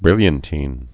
(brĭlyən-tēn)